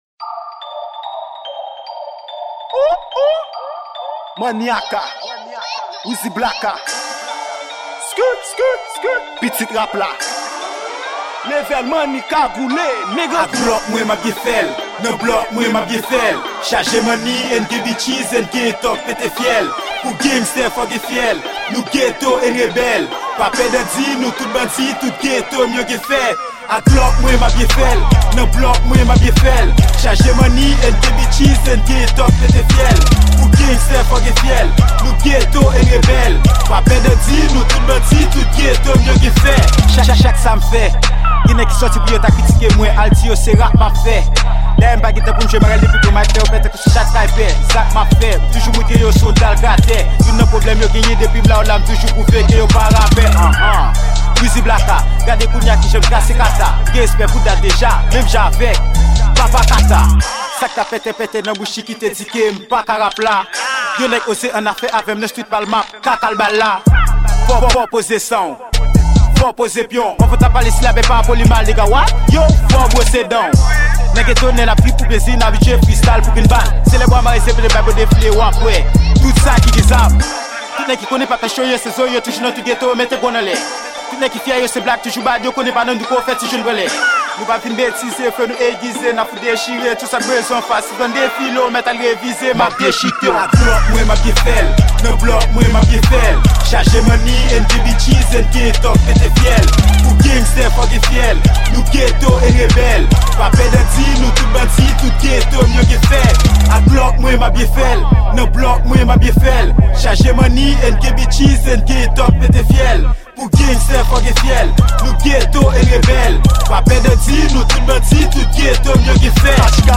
Genre : rap